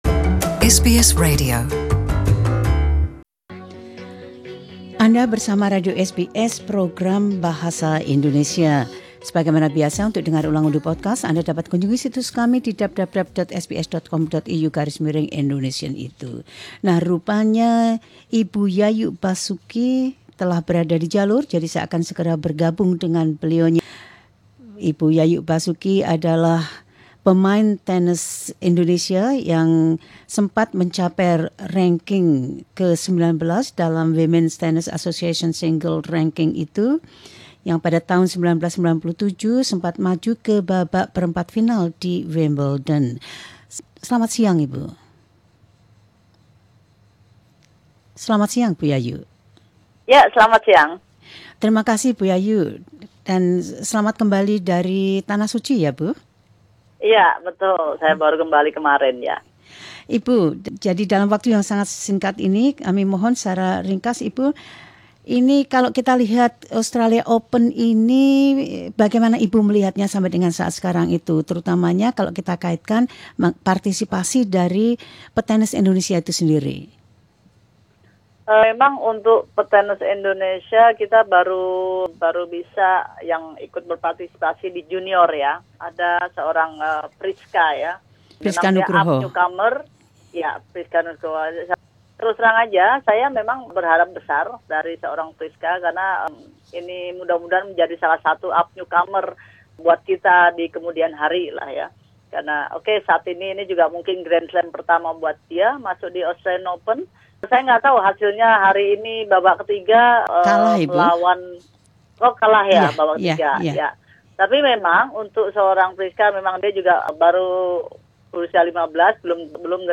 Legislator, pelatih tenis danpemain Indonesia dengan peringkat tertinggi dalam sejarah tenis, berbicara tentang Kejuaraan Tenis Terbuka Australia dan jalan ke depan untuk tenis perempuan di Indonesia.
Pemain tenis legendaris Indonesia Yayuk Basuki menjawab semuanya itu dan pertanyaan terkait lainnya.